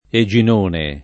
Eginone [ e J in 1 ne ]